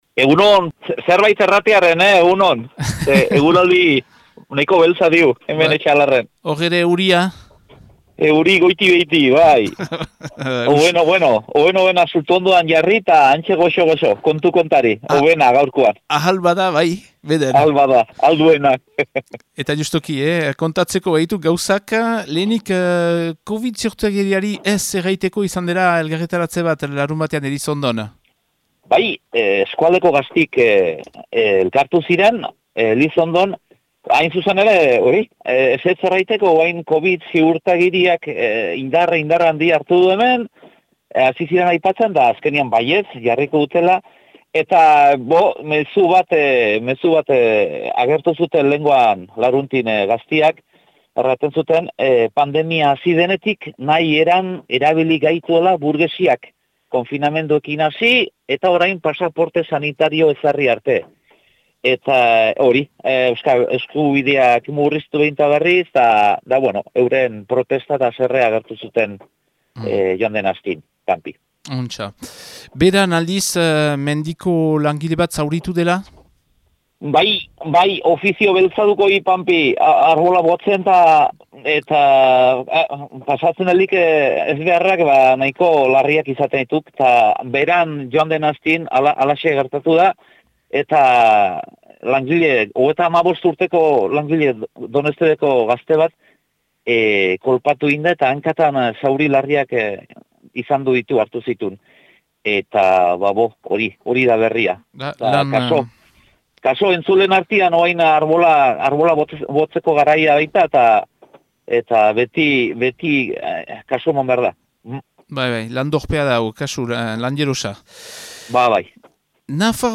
Abenduaren 2ko Etxalar eta Baztango berriak